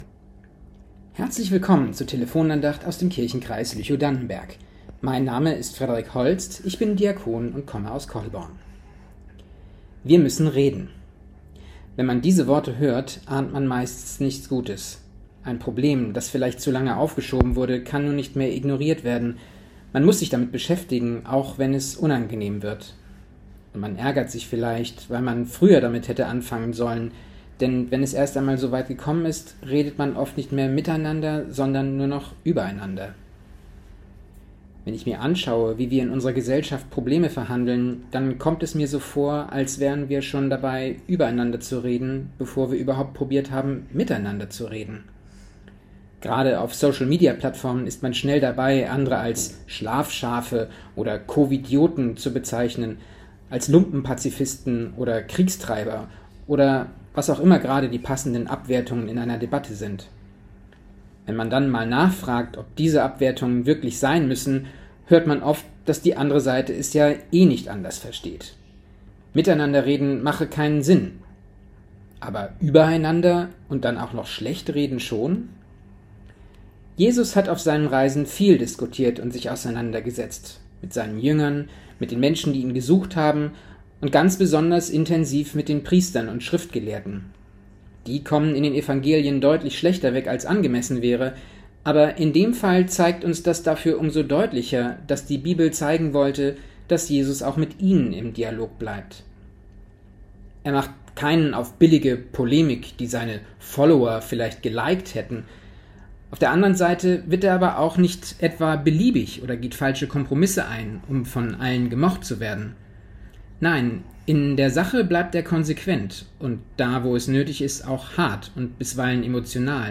Wir müssen reden ~ Telefon-Andachten des ev.-luth. Kirchenkreises Lüchow-Dannenberg Podcast